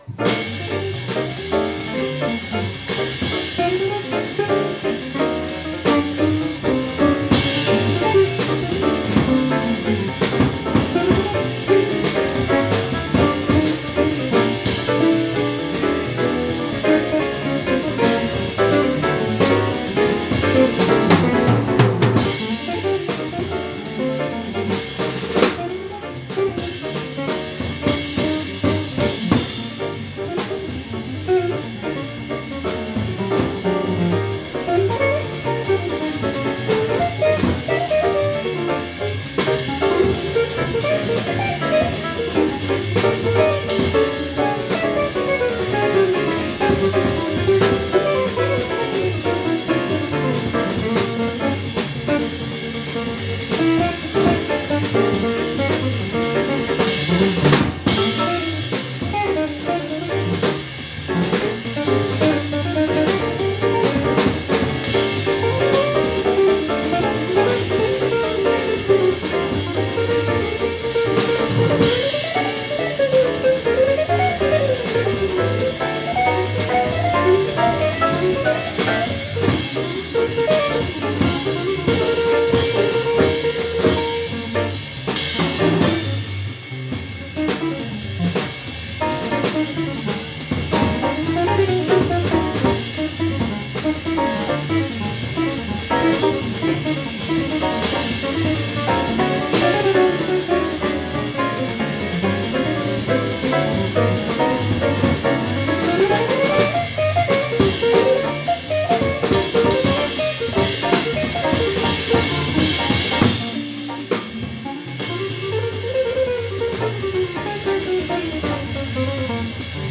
Jazz
Jamming
bootleg recording
unknown bass and drums